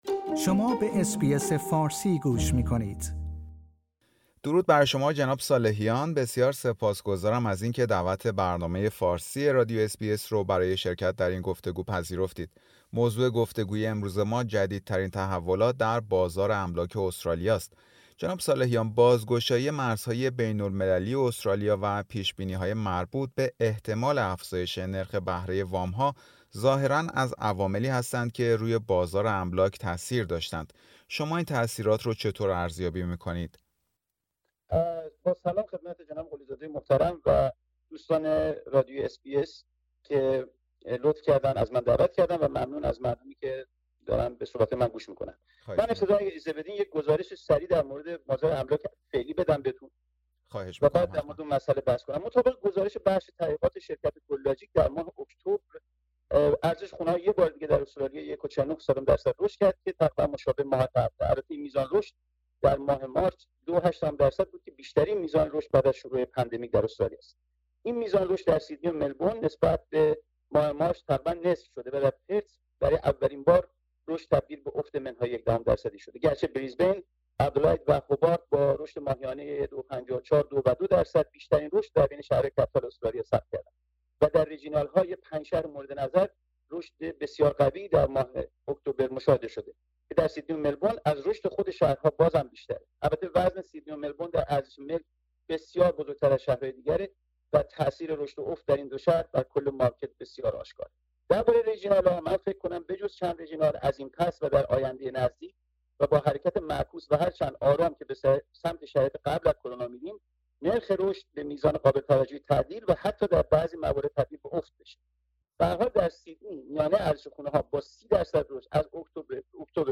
مصاحبه رادیو SBS فارسی